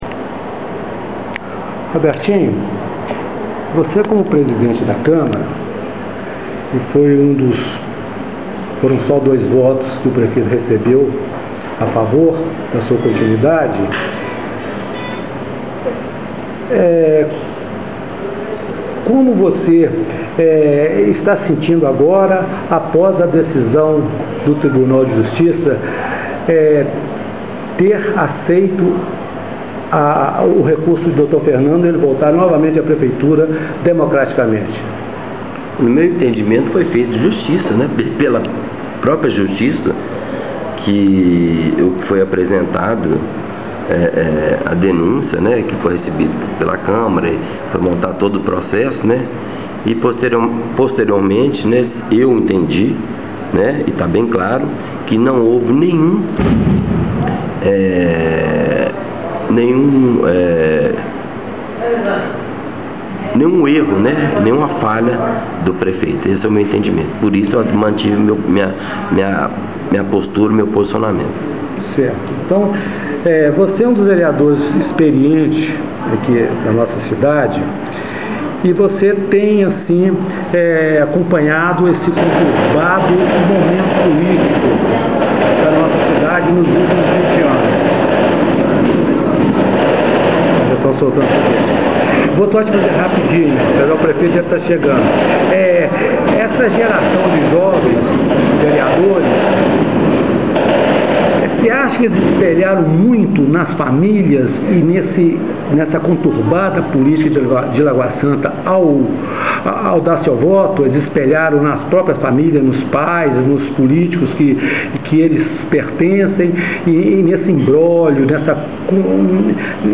entrevista_presidente_camara_robertinho.mp3